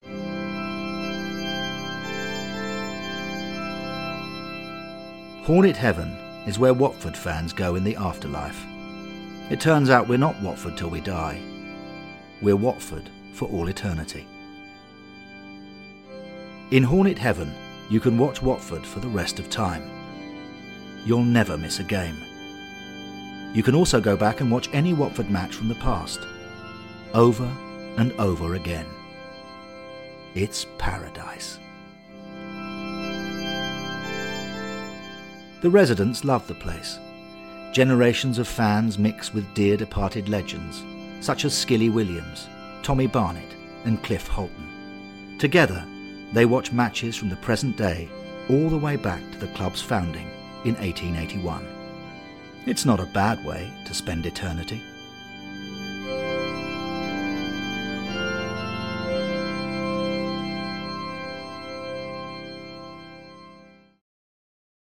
A 60-second trailer for the Hornet Heaven audio stories.